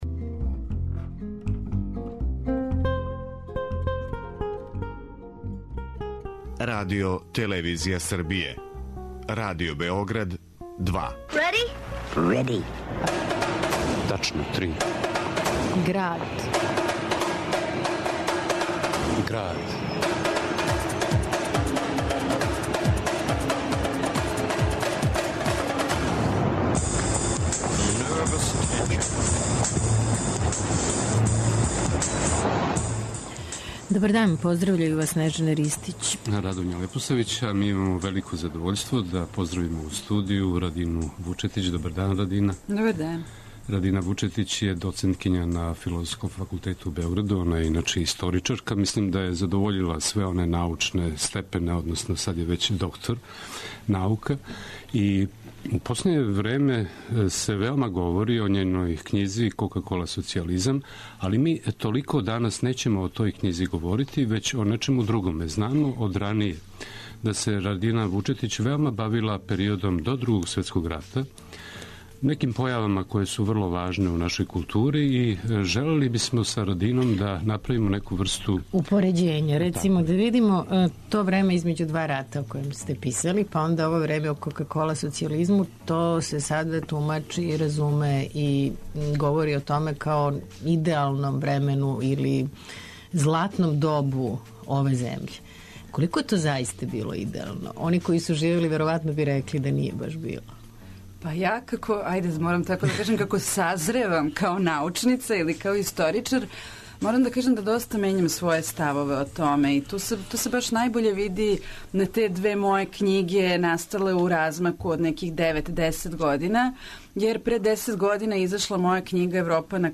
биће емитован и документарно-драмски фичер Побеђени Победник о полемикама поводом Мештровићевог споменика